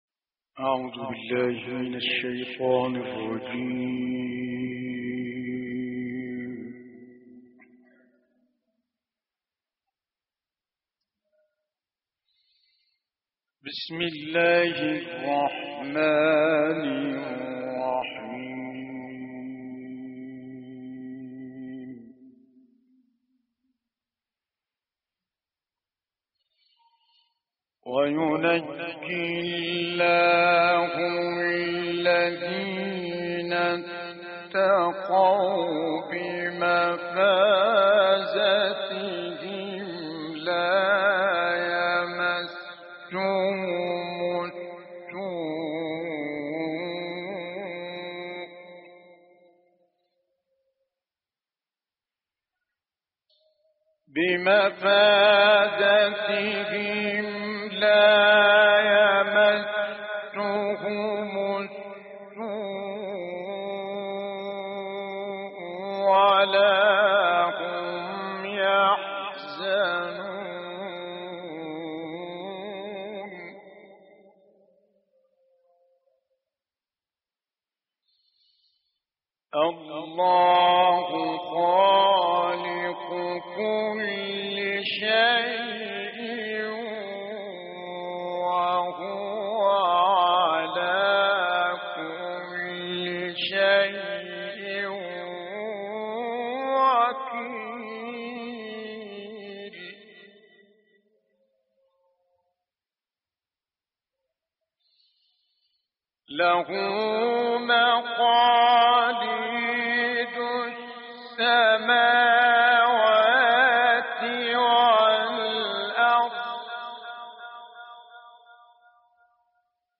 دانلود قرائت سوره های زمر آیات 61 تا 70 و بلد آیات 1 تا 18 - استاد متولی عبدالعال